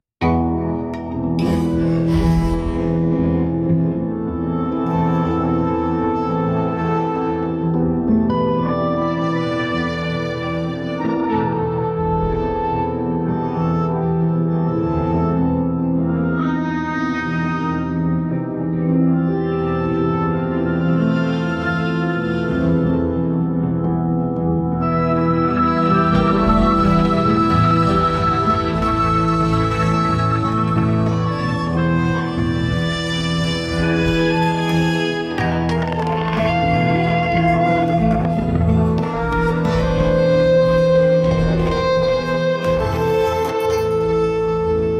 Prompt : Calm western music, cinematic, soundtrack